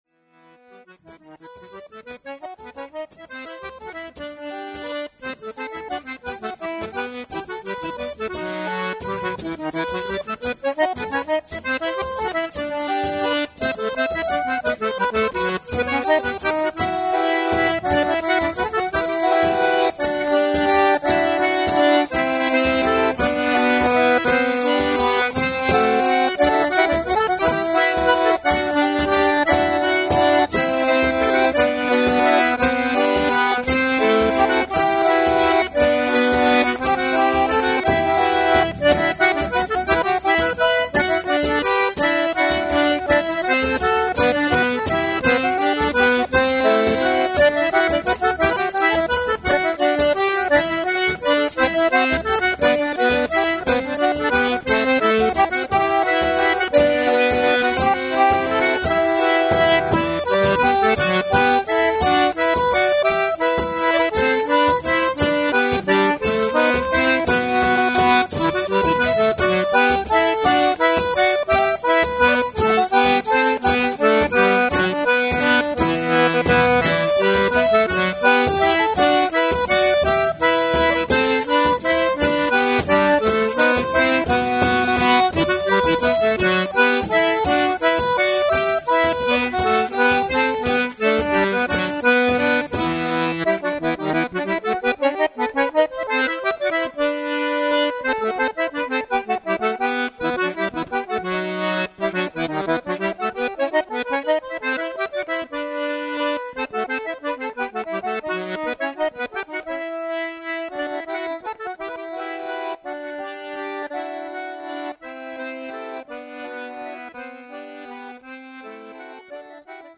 Valsa **
acordeon, cornamusas, flautas, percussions, cant
guitarras, percussions, cant